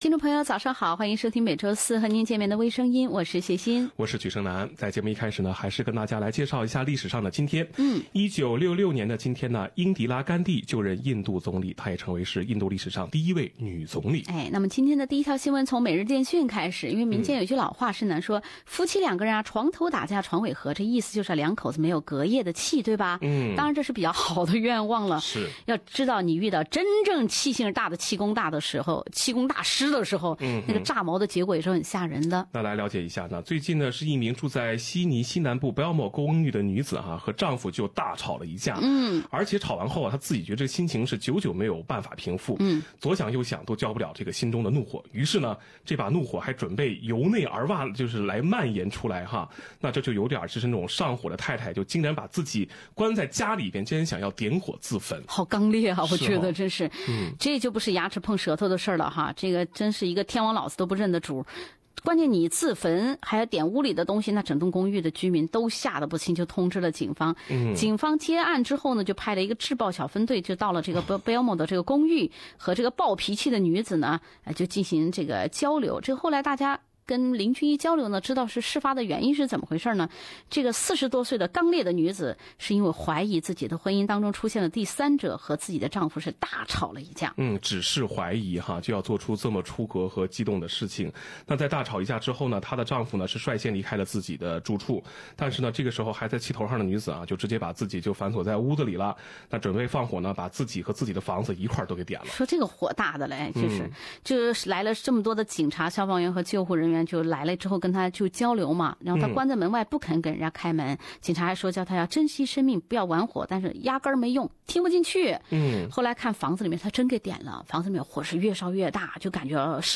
一起看看这个魔性的视频： 另类轻松的播报方式，深入浅出的辛辣点评；包罗万象的最新资讯；倾听全球微声音。